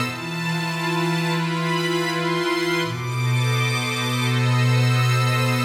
AM_80sOrch_85-E.wav